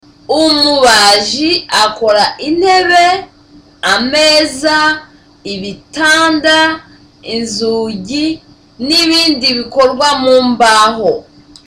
Dialogue: A conversation between Tereza and Sonita
(Seriously)